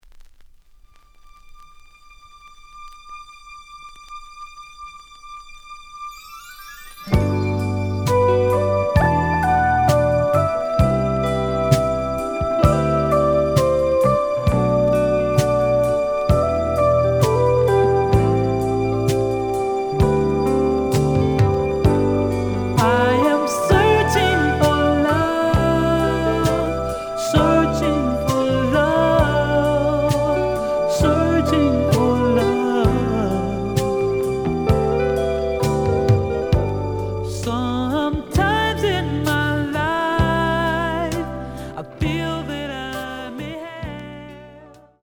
試聴は実際のレコードから録音しています。
●Genre: Disco
●Record Grading: VG+ (A面のラベルに書き込み。多少の傷はあるが、おおむね良好。)